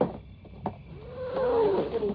Jones leaps out, Ripley screams.
Jones clearly disagrees with her actions.